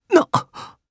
Arch-Vox_Damage_jp_01.wav